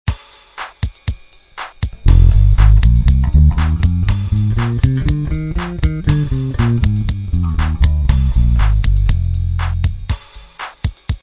Cliquer sur Ex et vous  aurez un exemple sonore en F (Fa).
DORIEN